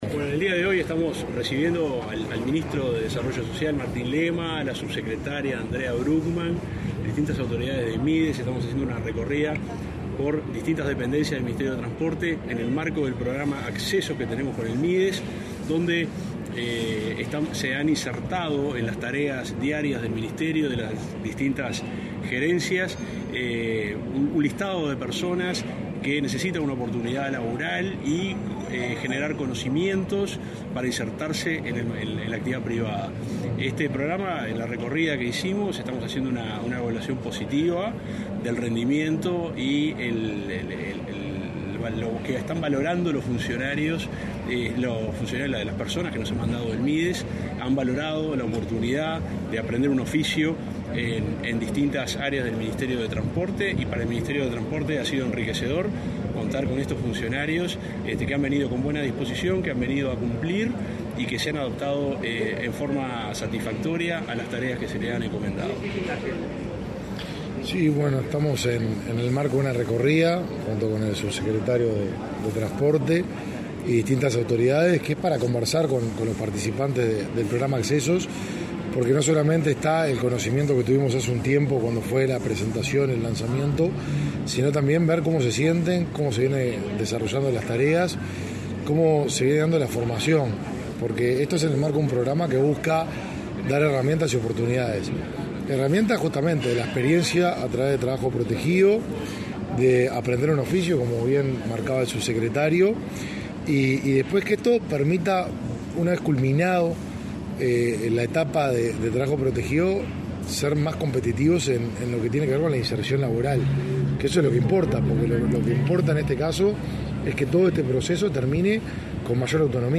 Declaraciones del titular del Mides, Martín Lema, y del subsecretario del MTOP, Juan José Olaizola